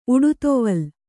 ♪ uḍutoval